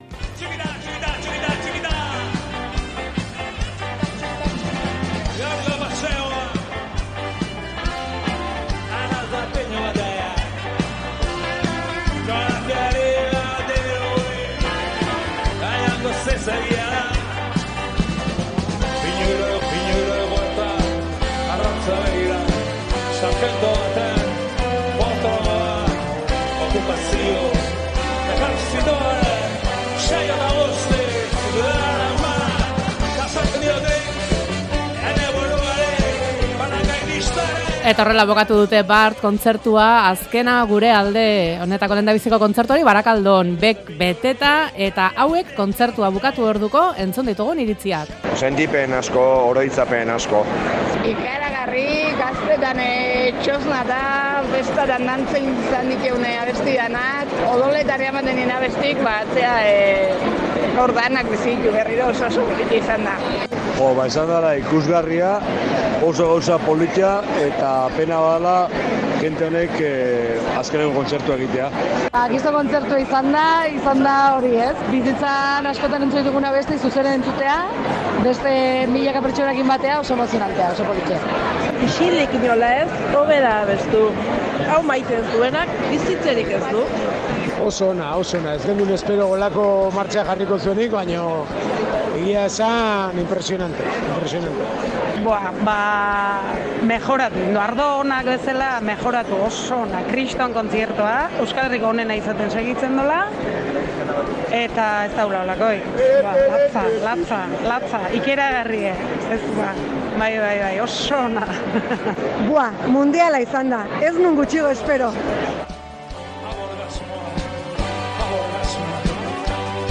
Audioa: Hertzainak taldeak BECen eskainitako lehen kontzertuaren inguruan iritzi bilduma osotu dugu. Entzuleek kontatu dutenez, lehenengo kontzertua bikaina izan da.